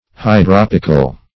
Search Result for " hydropical" : The Collaborative International Dictionary of English v.0.48: Hydropic \Hy*drop"ic\, Hydropical \Hy*drop"ic*al\, a. [L. hydropicus, Gr.